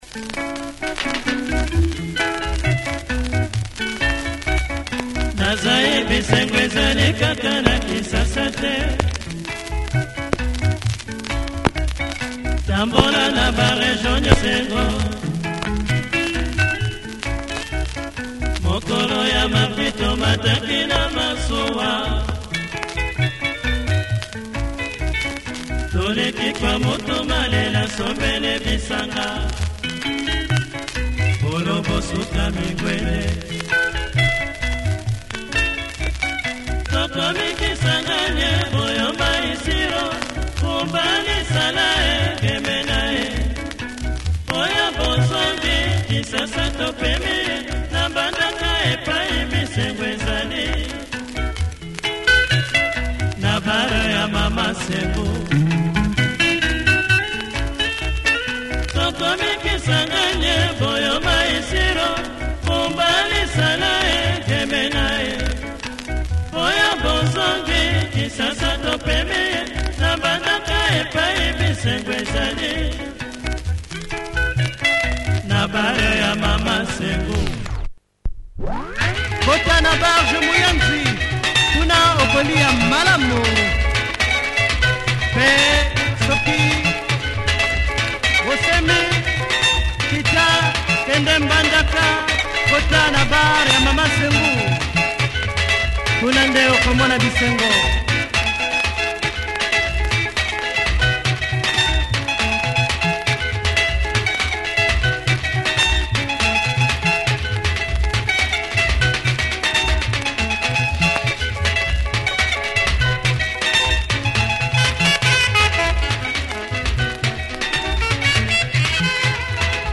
Nice lingala.